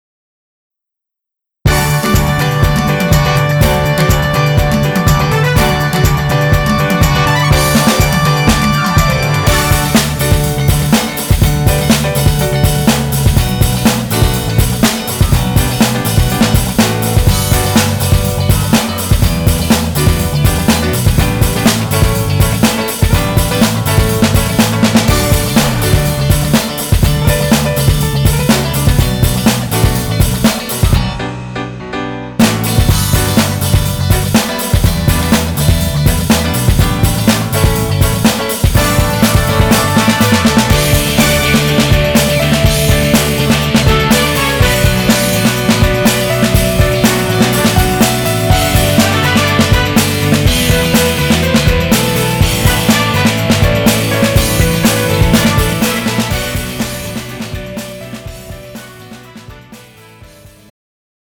음정 멜로디MR
장르 축가 구분 Pro MR